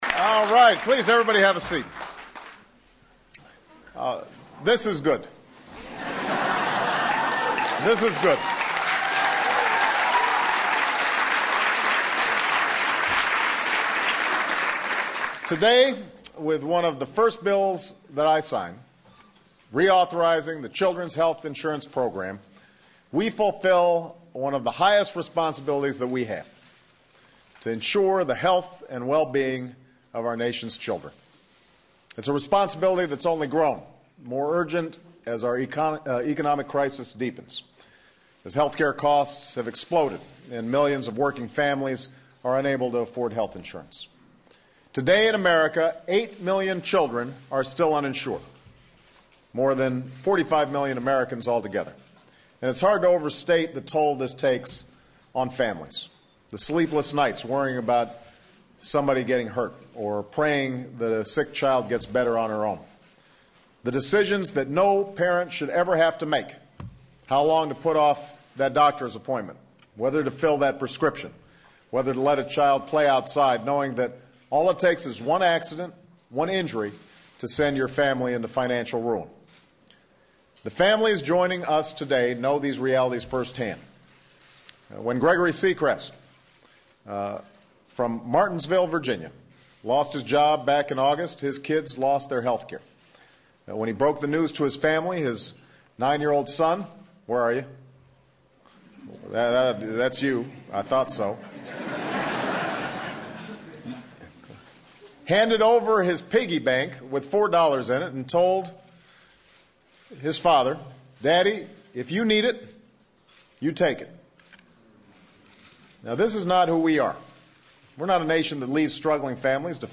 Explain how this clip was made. Children's Health Insurance Program Reauthorization Act of 2009 (United States) Child health insurance Government insurance Material Type Sound recordings Language English Extent 00:10:36 Venue Note Broadcast on C-SPAN, Feb. 4, 2009.